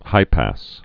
(hīpăs)